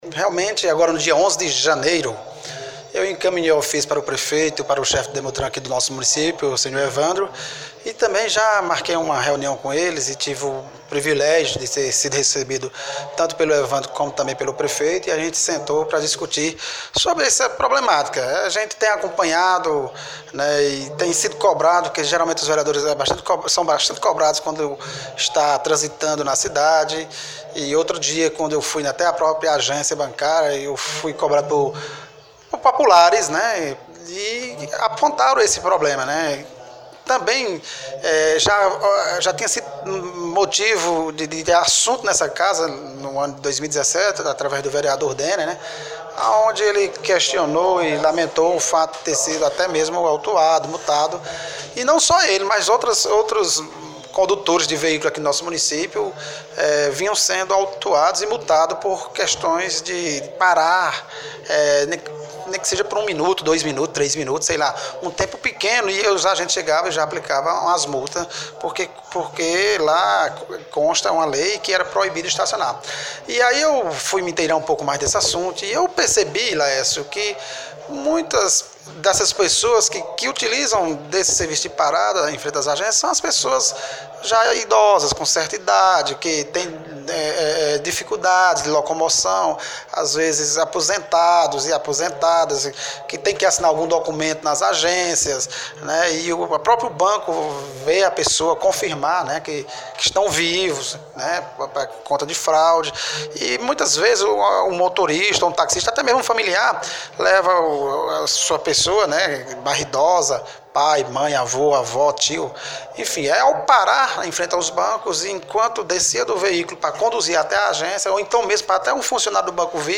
Acompanhe o que o presidente comentou em um áudio da entrevista concedido a Cultura FM 96,3: